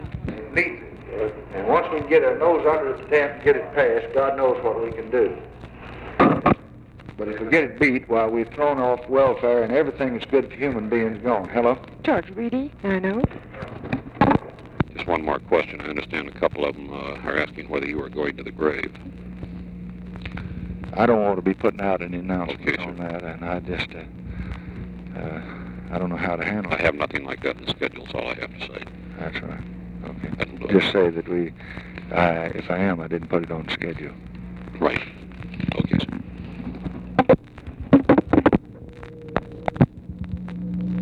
Conversation with GEORGE REEDY and OFFICE CONVERSATION, May 28, 1964
Secret White House Tapes